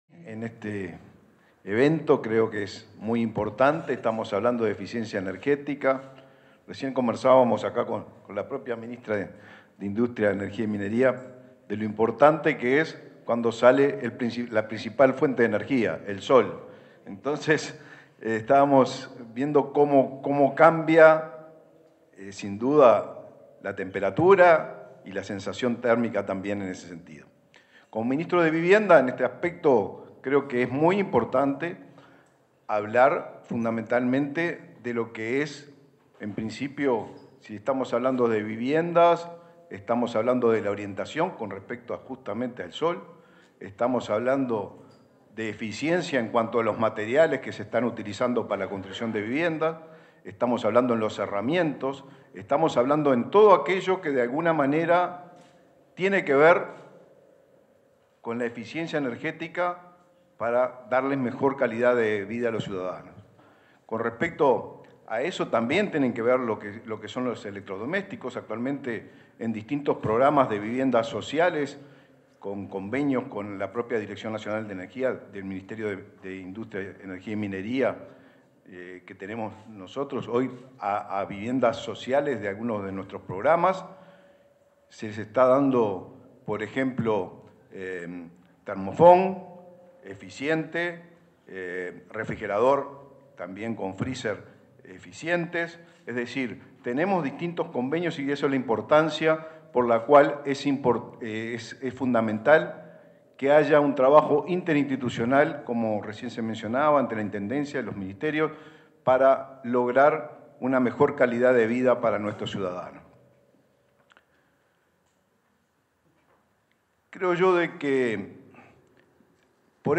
Lanzamiento del Modelo de Cálculo de Desempeño Energético para Viviendas 17/06/2024 Compartir Facebook X Copiar enlace WhatsApp LinkedIn En el marco de la presentación de un programa informático para evaluar el desempeño energético, este 17 de junio, se expresaron los ministros de Industria, Energía y Minería, Elisa Facio, y Vivienda y Ordenamiento Territorial, Raúl Lozano.